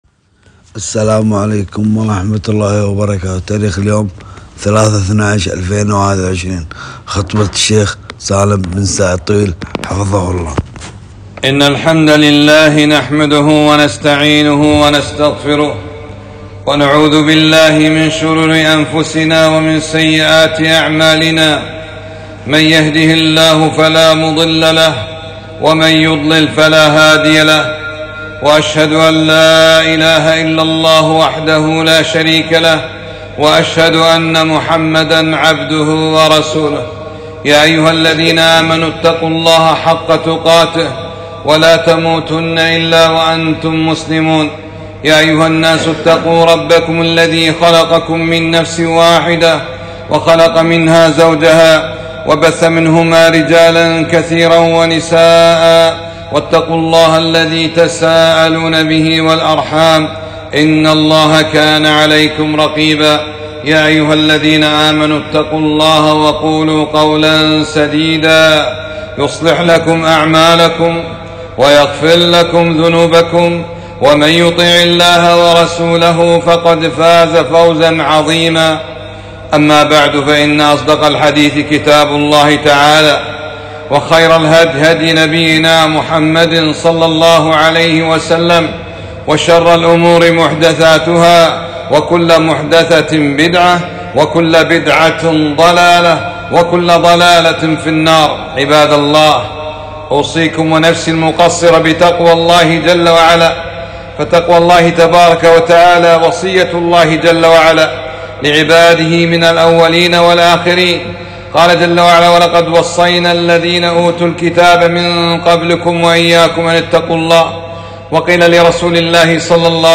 خطبة - (أليس الله بأحكم الحاكمين) ؟